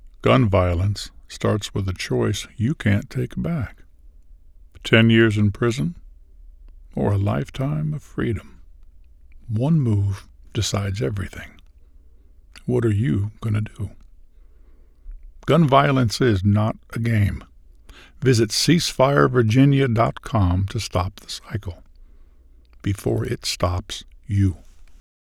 Demos
Senior